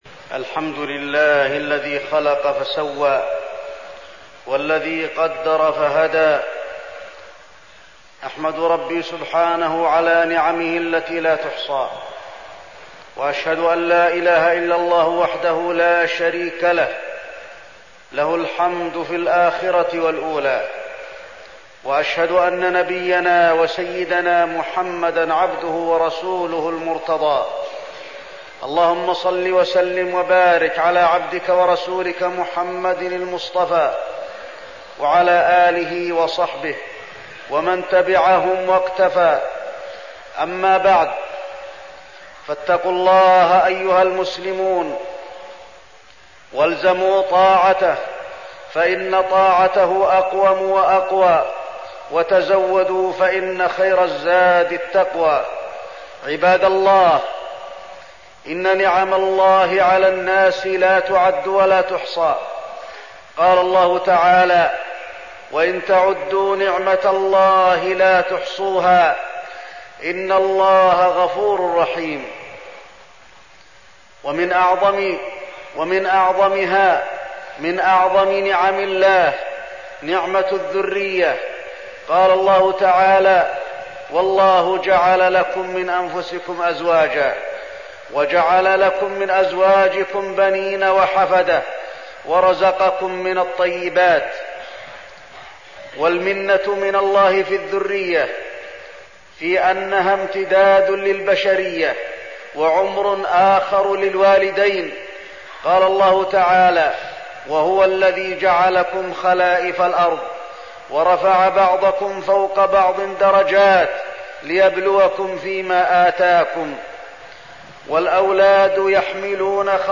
تاريخ النشر ١٤ ربيع الأول ١٤١٨ هـ المكان: المسجد النبوي الشيخ: فضيلة الشيخ د. علي بن عبدالرحمن الحذيفي فضيلة الشيخ د. علي بن عبدالرحمن الحذيفي تربية الأولاد في الإسلام